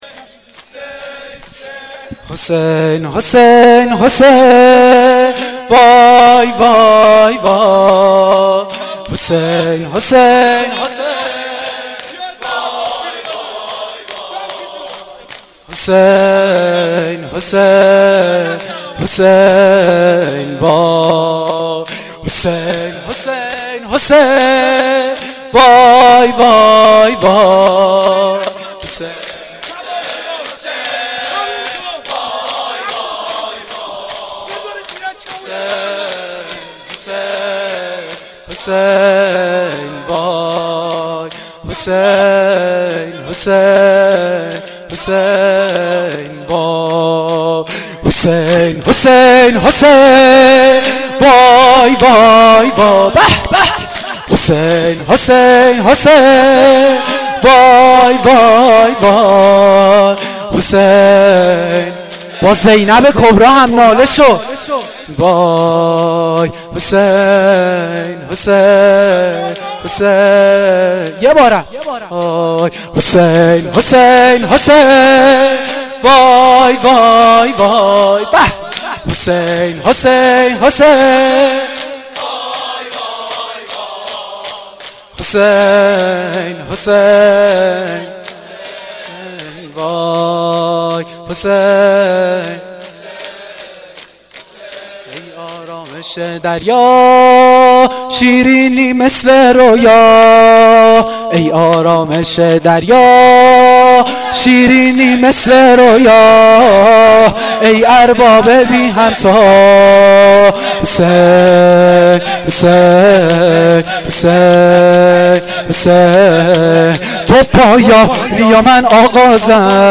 شور شب عاشورا محرم1393